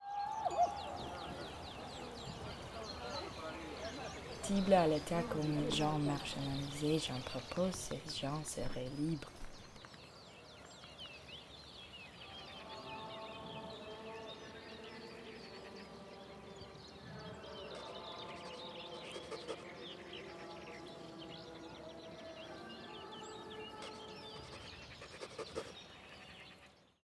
This soundscape features sounds of a rural village, with layers representing Cézanne and important biographical notes. Towards the beginning of the track, we can hear a woman’s voice speaking French; Cézanne identified his mother as foundational in his upbringing and important to the development of his personality. Also present is audio of a Spanish Catholic mass, as Cézanne was trained by a Spanish monk in his youth. We can also hear pencil scratches to note the unfinished nature of the painting and the label’s note of exposed graphite.